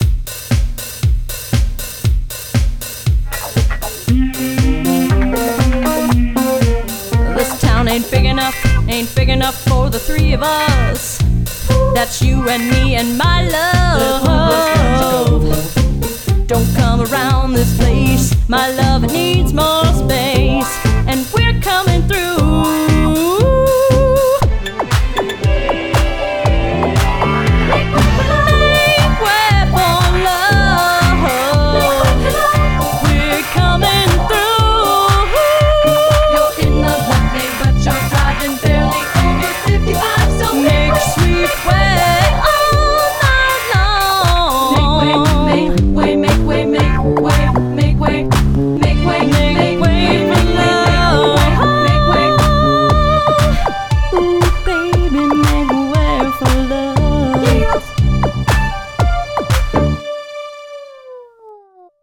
backup vocals)